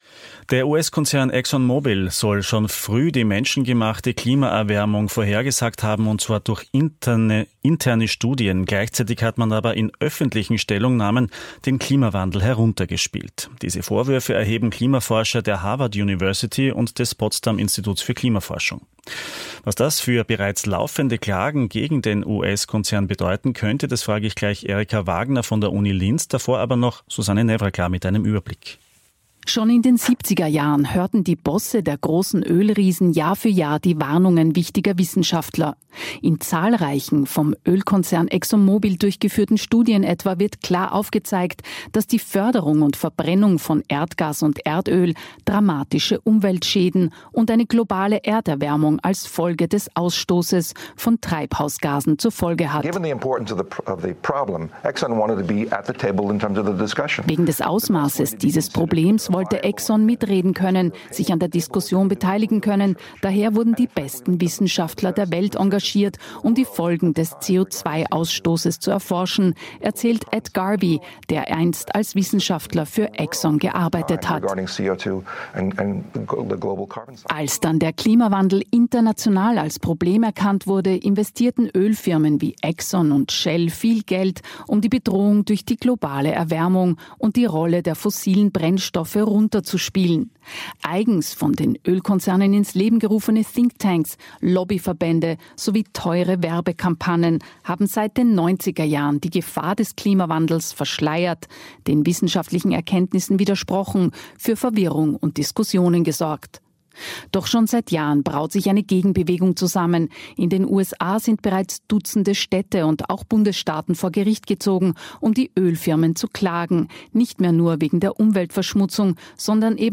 Radiobeitrag über